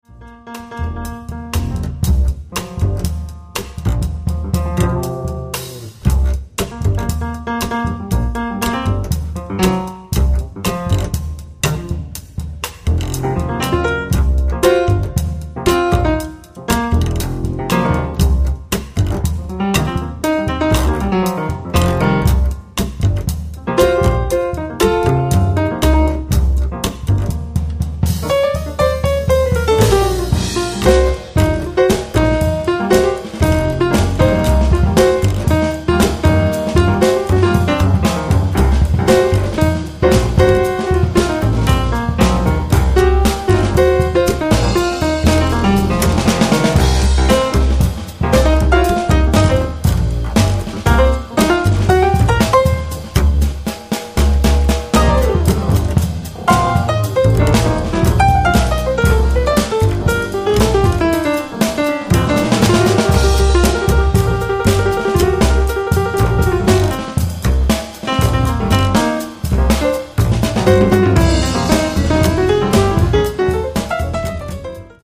piano
batteria
contrabbasso